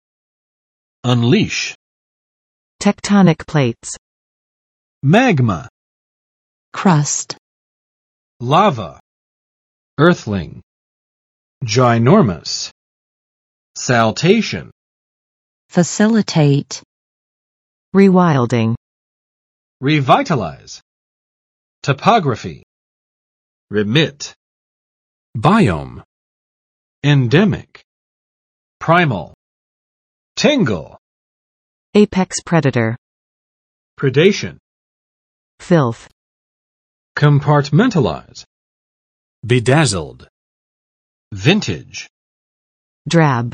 [ʌnˋliʃ] v. 释放；宣泄（感情）